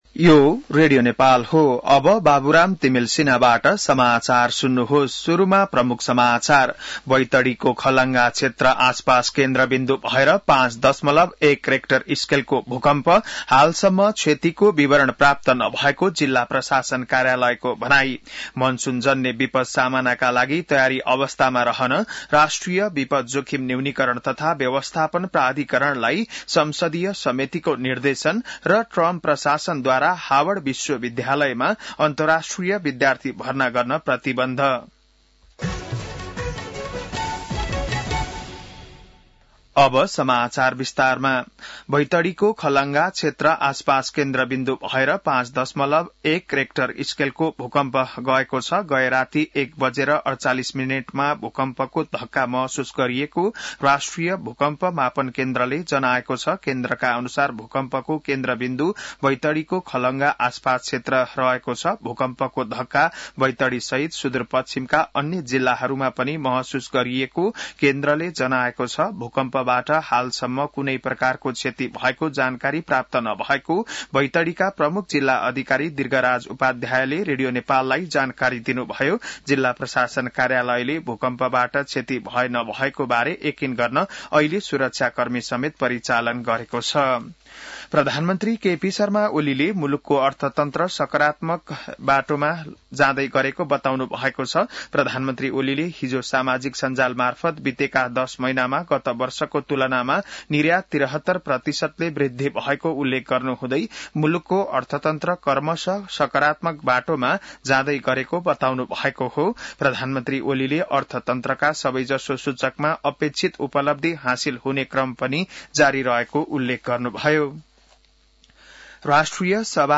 बिहान ९ बजेको नेपाली समाचार : ९ जेठ , २०८२